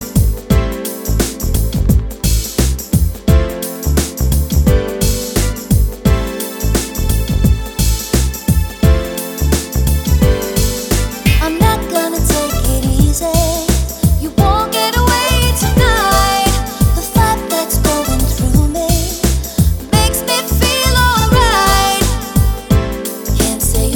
For Solo Male Pop (1990s) 3:40 Buy £1.50